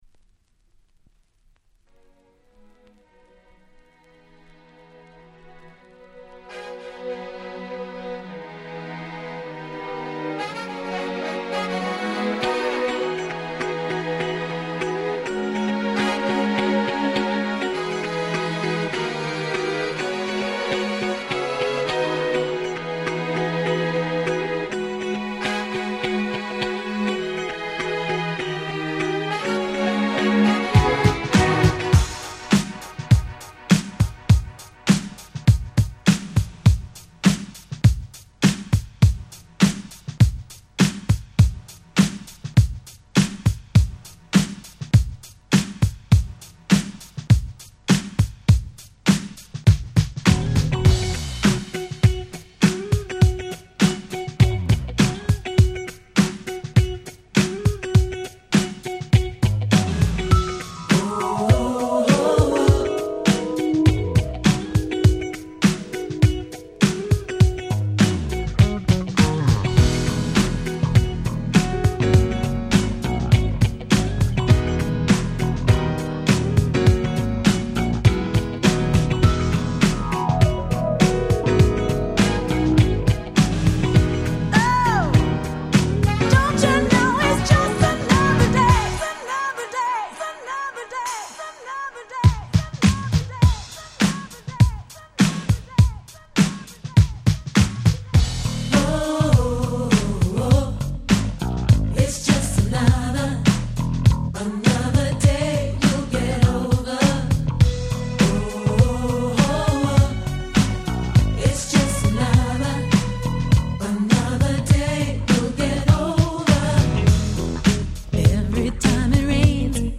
UK R&B Classics !!